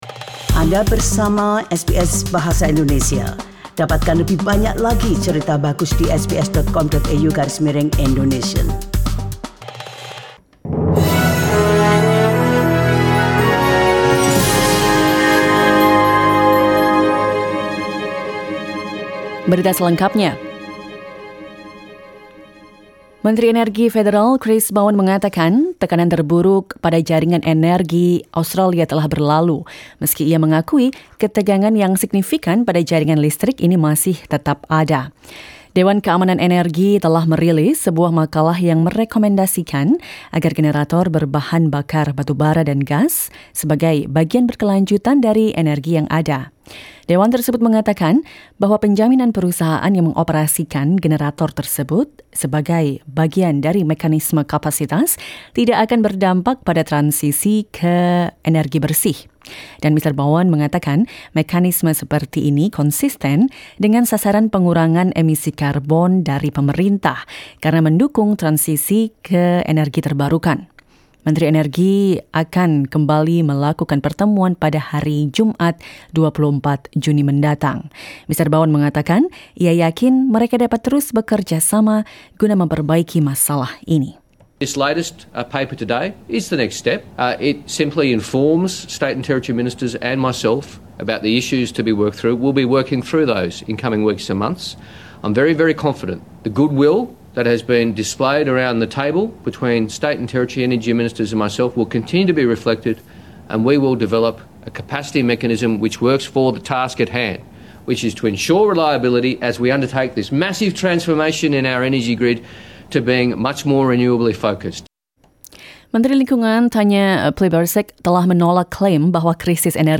SBS Radio news in Indonesian - 20 June 2022